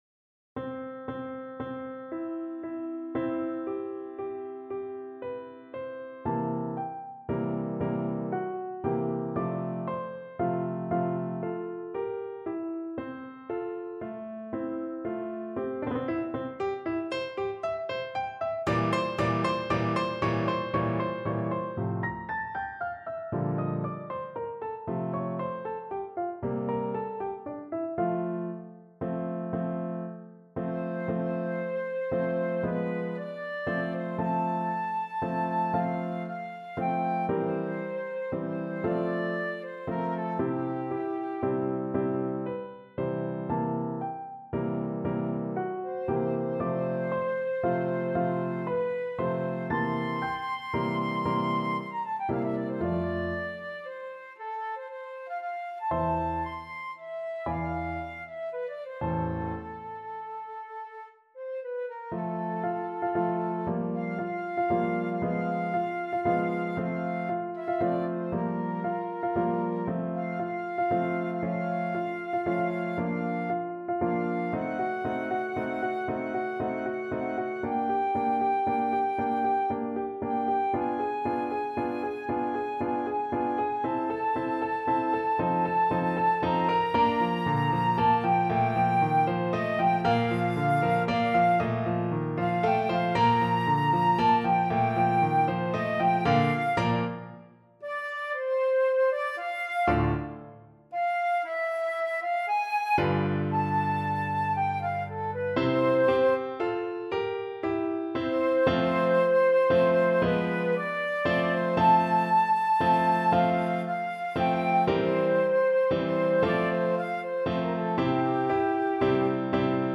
Flute version
6/8 (View more 6/8 Music)
Andantino (=116) (View more music marked Andantino)
Flute  (View more Advanced Flute Music)
Classical (View more Classical Flute Music)